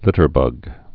(lĭtər-bŭg)